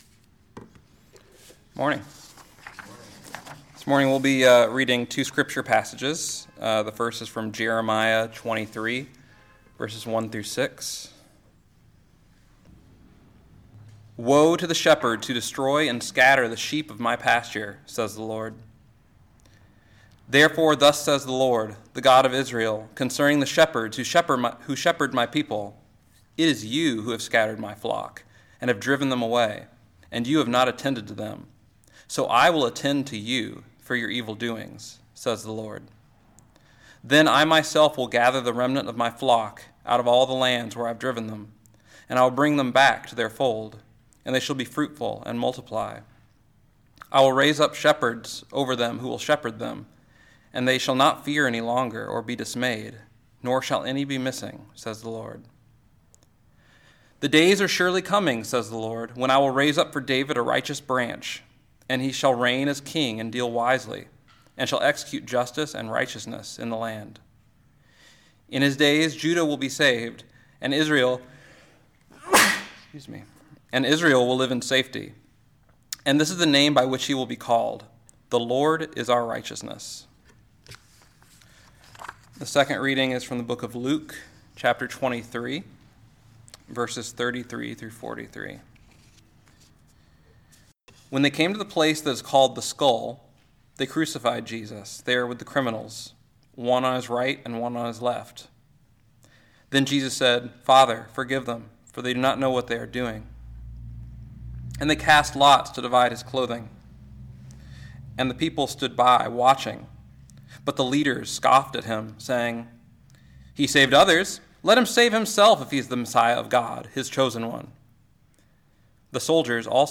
Listen to the most recent message from Sunday worship at Berkeley Friends Church, “Jesus is King.”